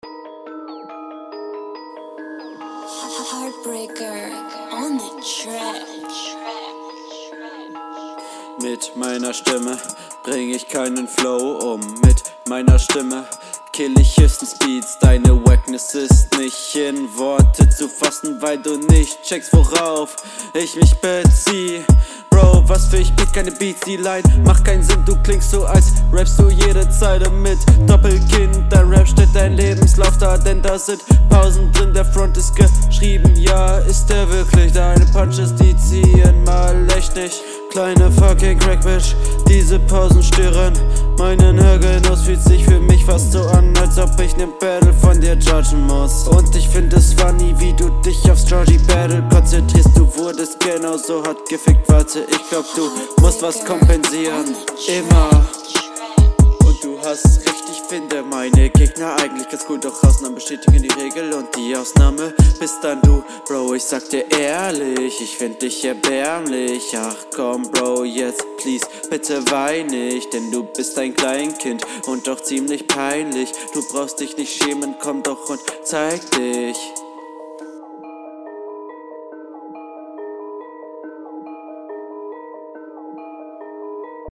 Da hab ich selbst nen besseren Flow wenn ich keinen Bock auf den Gegner habe.